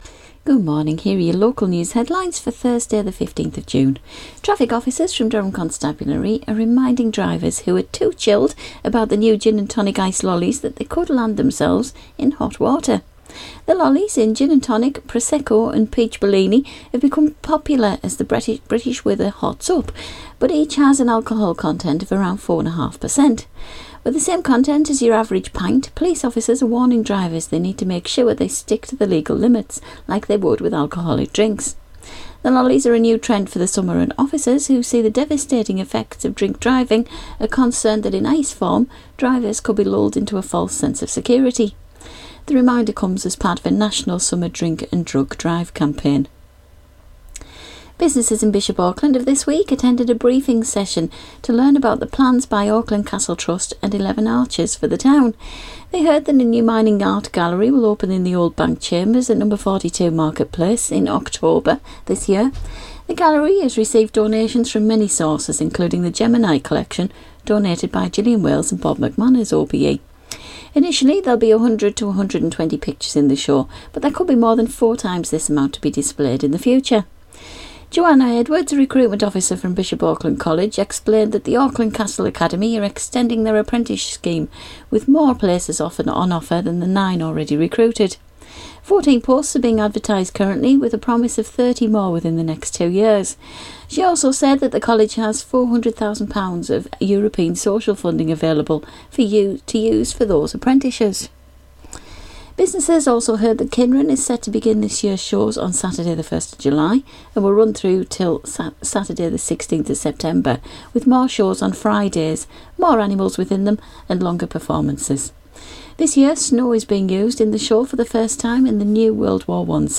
Local News Headlines - Thursday 15 June 2017